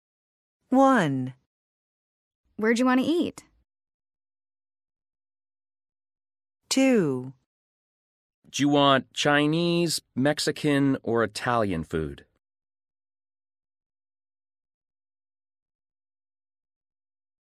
PRONUNCIATION: Listening for Do you want to… ……?